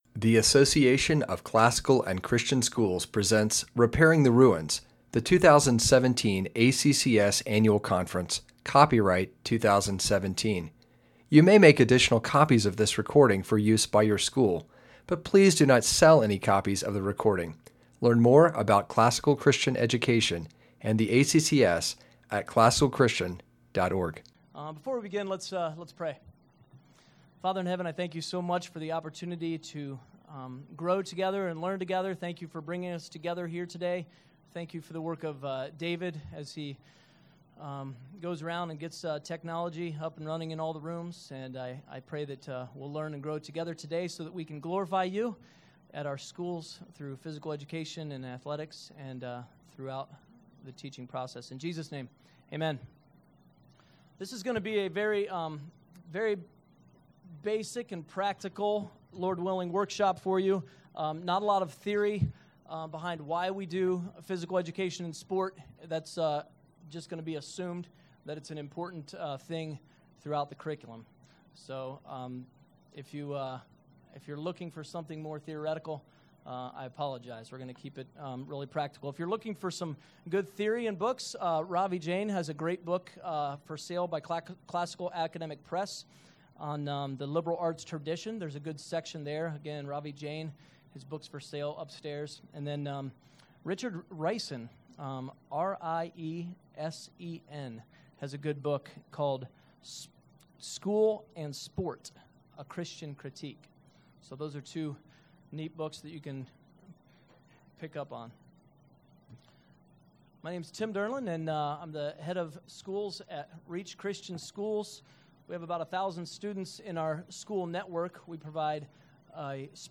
2017 Workshop Talk | 0:51:58 | All Grade Levels
This workshop focuses on the importance of training the body through a trivium-based approach to the curriculum of P.E. and sports. Speaker Additional Materials The Association of Classical & Christian Schools presents Repairing the Ruins, the ACCS annual conference, copyright ACCS.